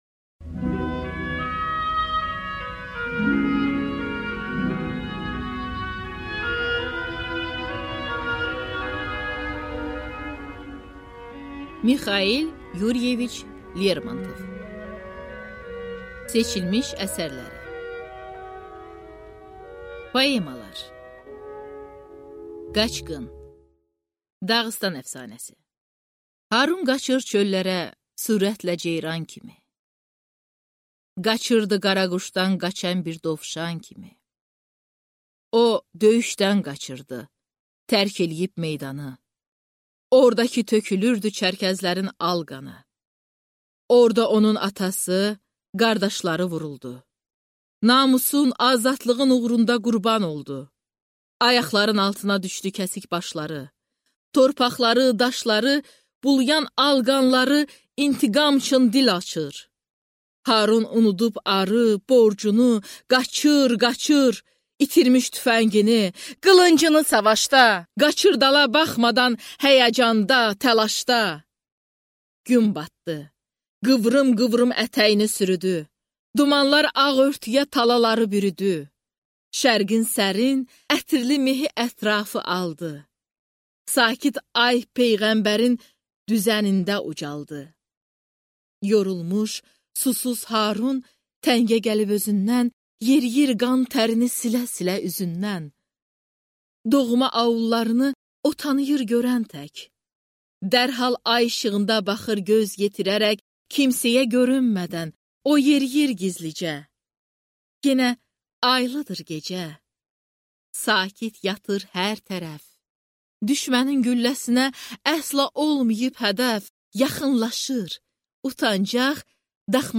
Аудиокнига Seçilmiş əsərlər | Библиотека аудиокниг